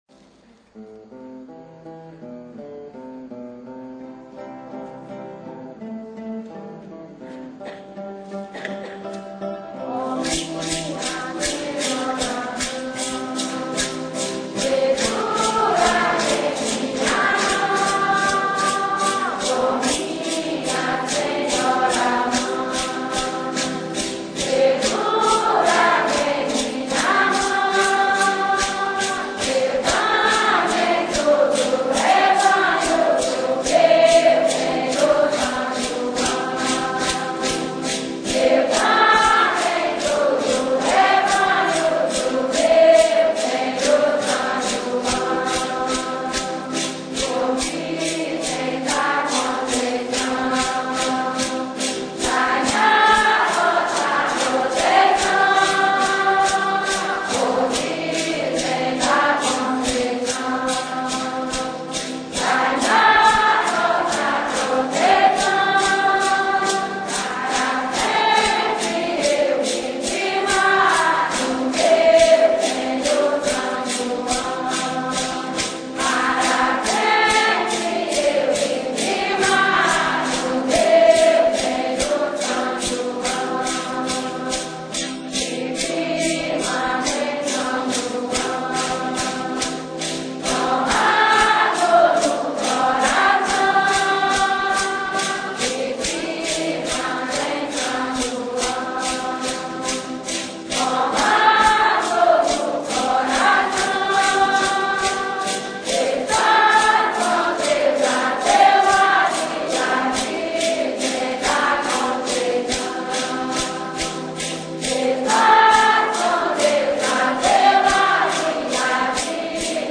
valsa